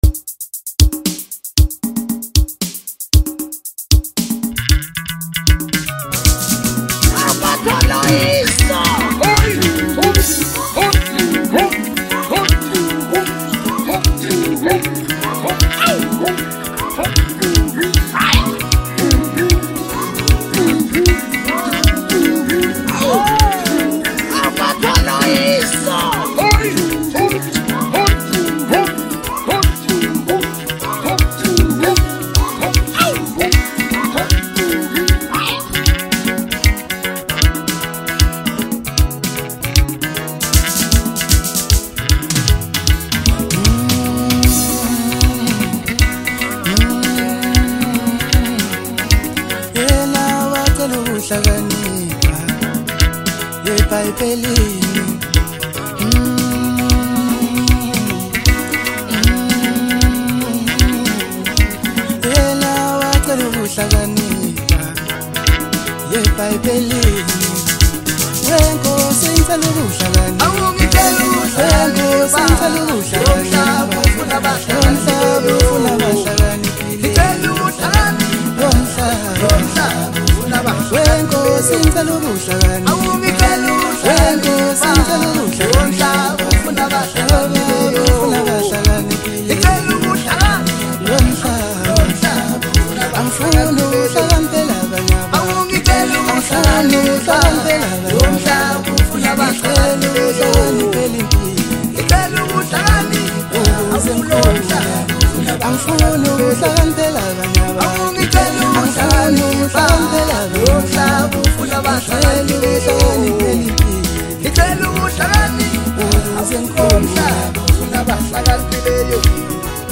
Home » Maskandi » DJ Mix » Hip Hop
South African music composer